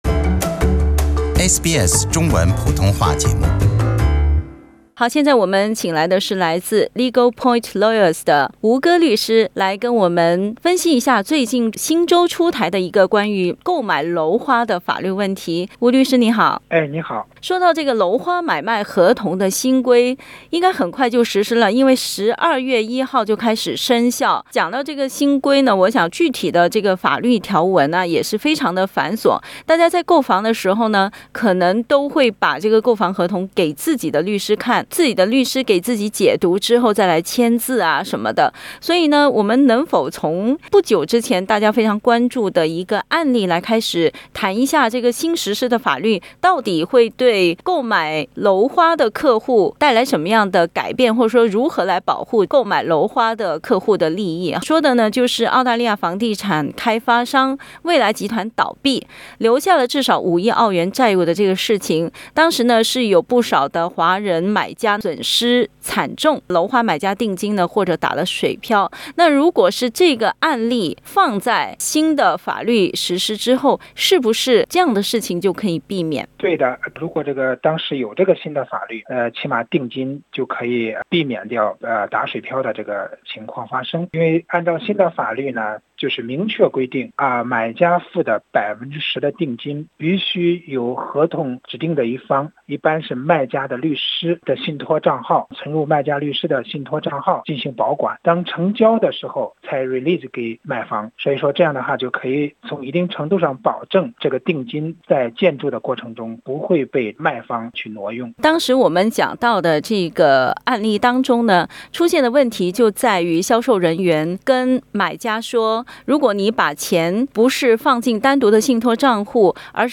（本节目为嘉宾观点，仅供参考。）